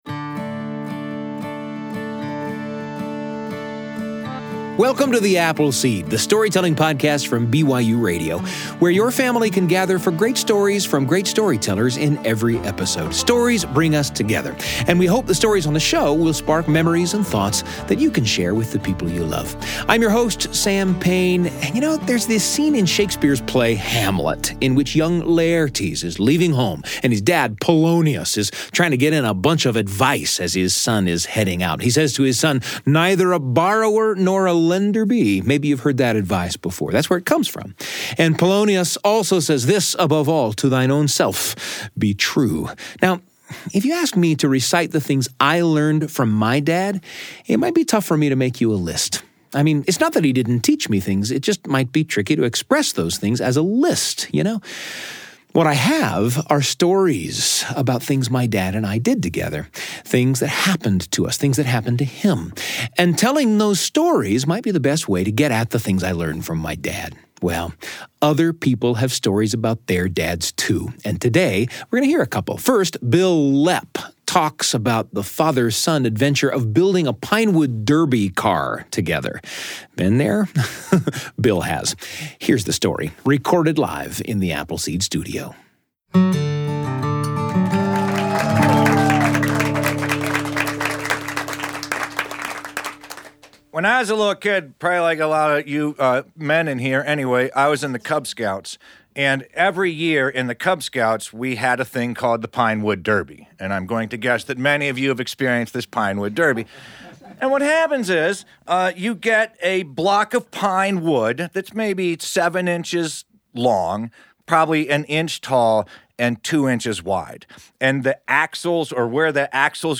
Gather your family for great stories from great storytellers about fathers who created lasting memories with their sons.